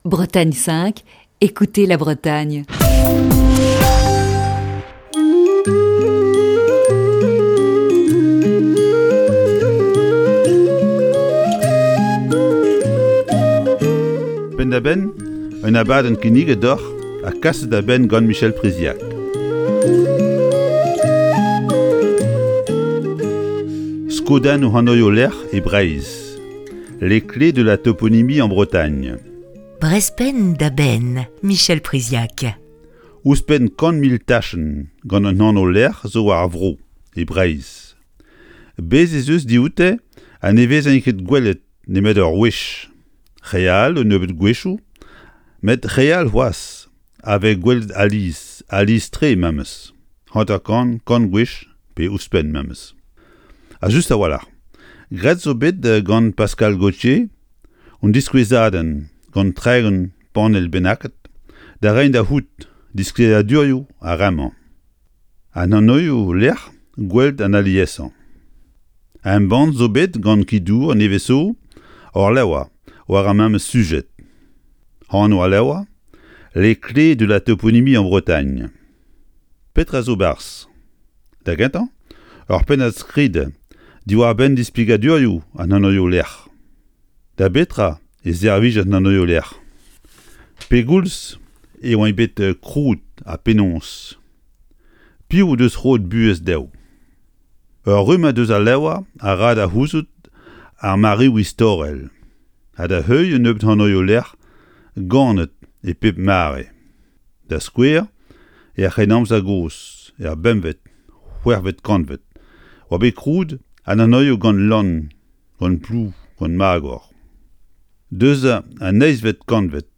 Chronique du 5 mars 2021.